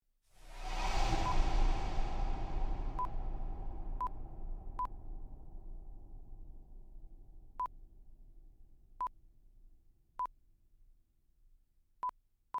Long Ghostly Whoosh 1 (sound FX)
Ghost Whoosh, Air Burst. Ship passing. Horror sounds. Spooky. Multimedia Sound Effects, Whooshes
Long_Ghostly_Whoosh_1_plip.mp3